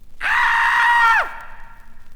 • one scream - male.wav
one_scream_-_male_7VM.wav